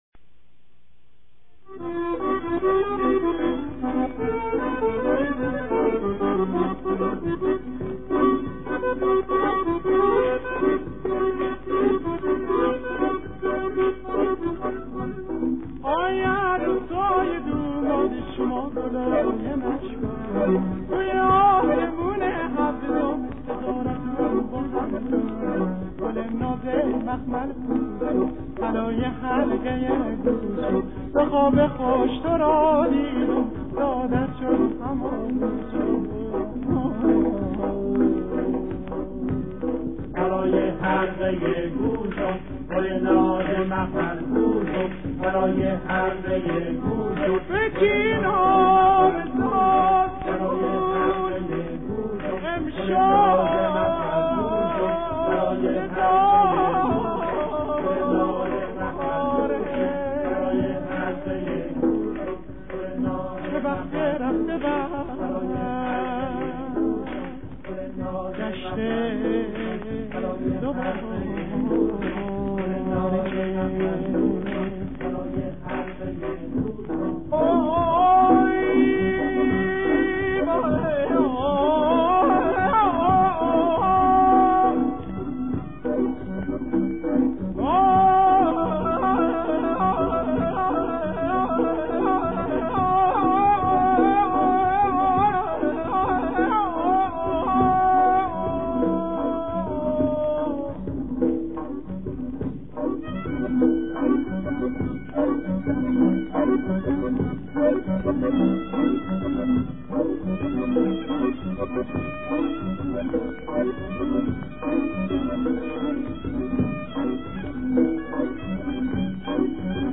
آهنگ قدیمی
دانلود آهنگ شاد عروسی